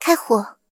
追猎者开火语音1.OGG